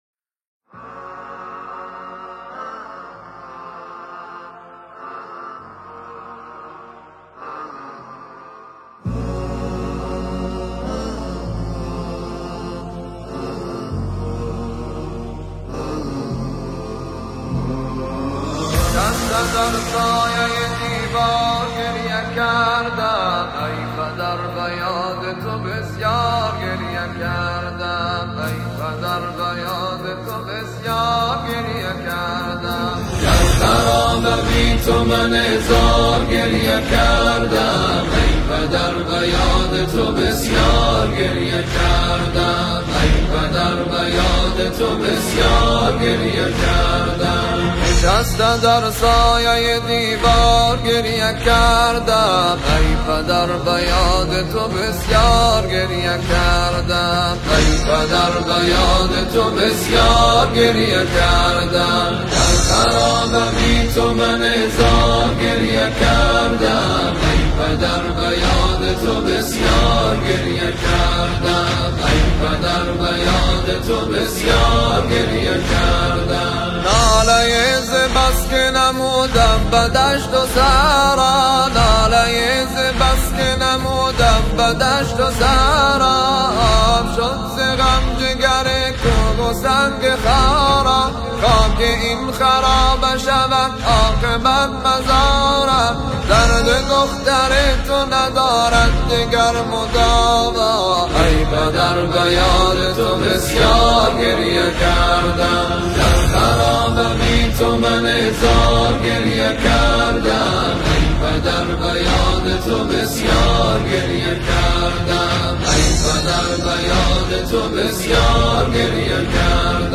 نوحه شهادت حضرت رقیه
مداحی شهادت حضرت رقیه